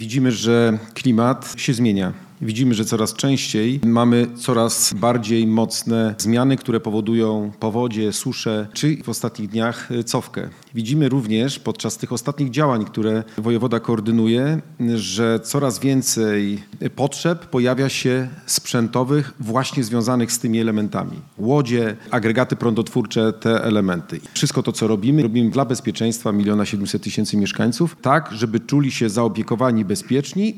Sygnatariuszem listu intencyjnego, podpisanego w imieniu administracji rządowej, jest wojewoda zachodniopomorski Adam Rudawski, który zwraca uwagę na rosnące potrzeby sprzętowe strażaków w obliczu zmian klimatycznych: